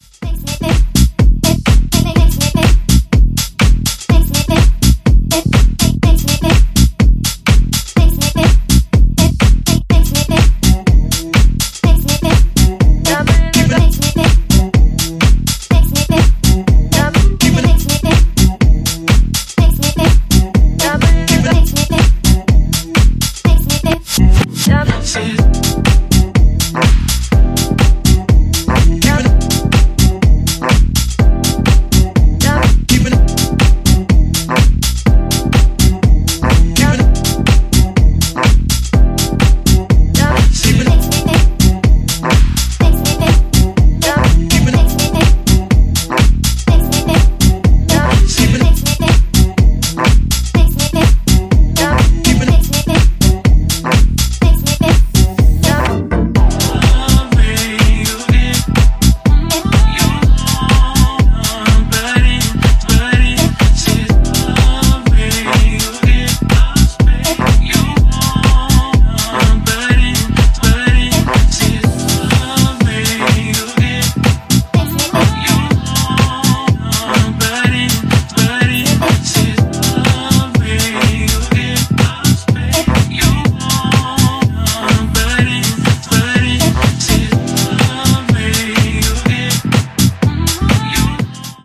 B面もポップUPハウスでリズミカルなハウス展開してます。
ジャンル(スタイル) HOUSE